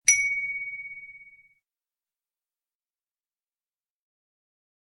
audio-chime-04.mp3